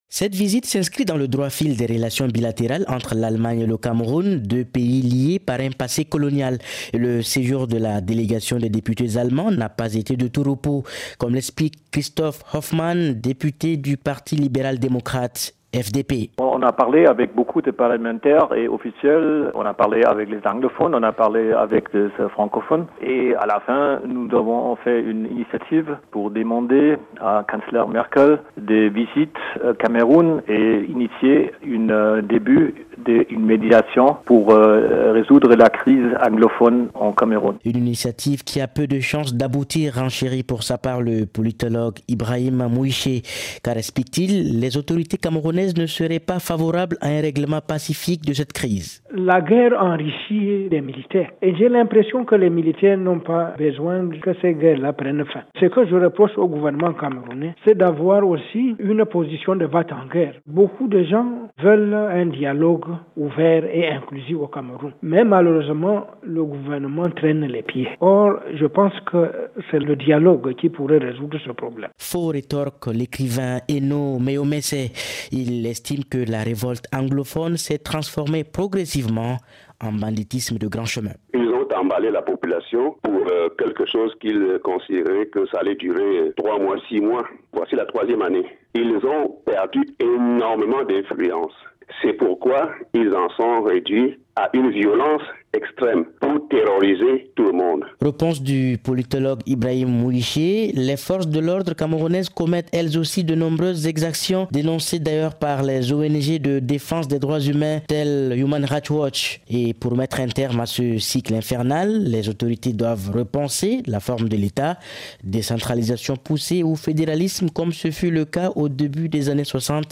Cette visite s’inscrit dans le droit fil des relations bilatérales entre l’Allemagne et le Cameroun, deux pays liés par un passé colonial. Et le séjour de la délégation des députés allemands n’a pas été de aaa tout repos, comme l’explique Christoph Hoffmann, député du Parti libéral-démocrate, FDP.
crise-anglophone-Christoph-Hoffmann-député-FDP.mp3